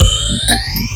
DUBLOOP 05-R.wav